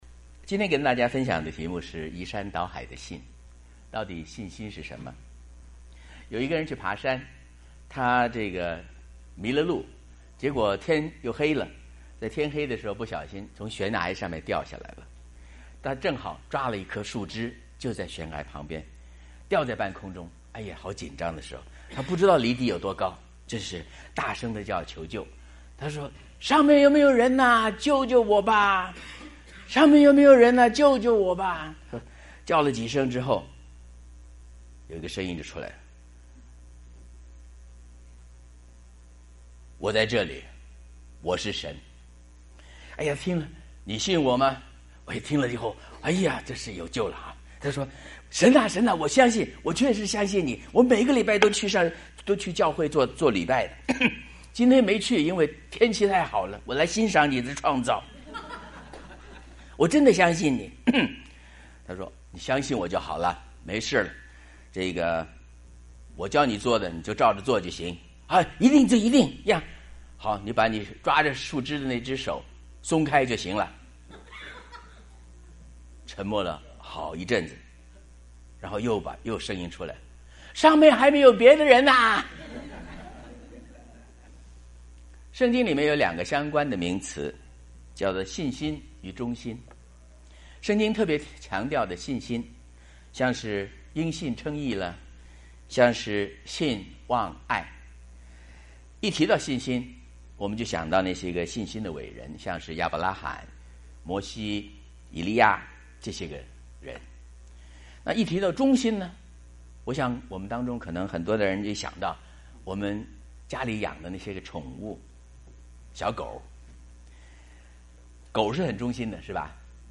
題目：”移山倒海的信心” 講員：